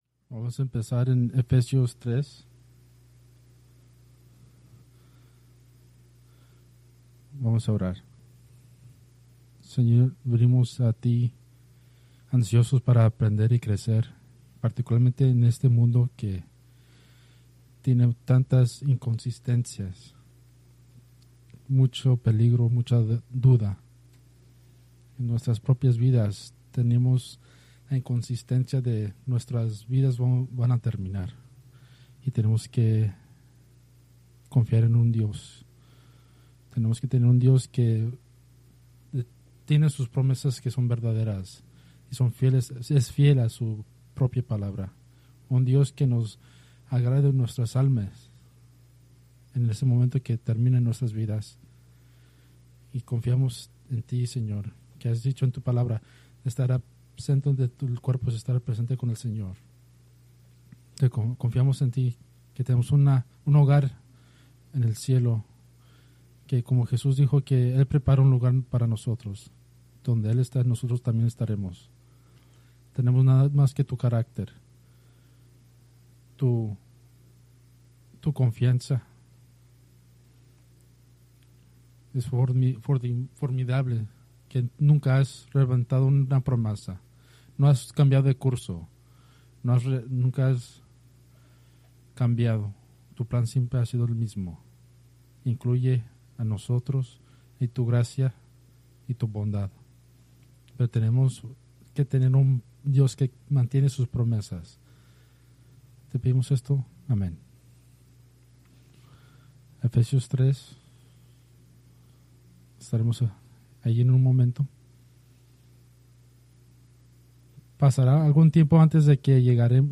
Preached September 21, 2025 from Escrituras seleccionadas